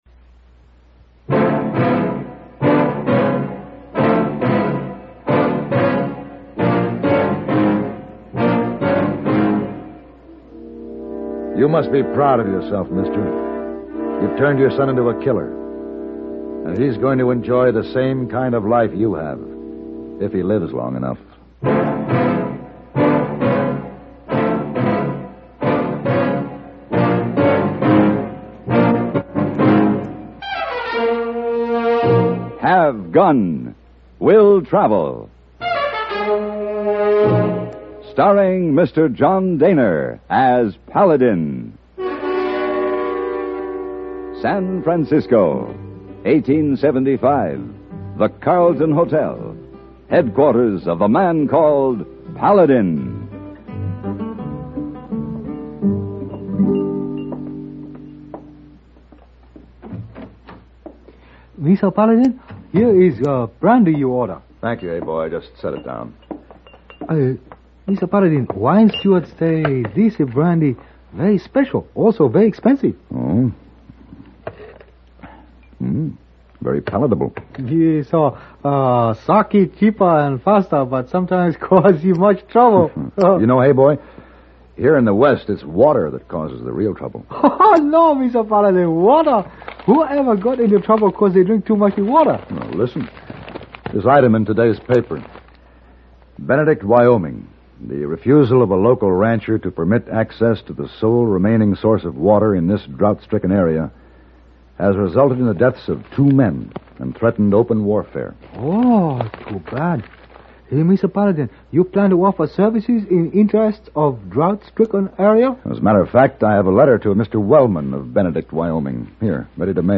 Will Travel Radio Program
Starring John Dehner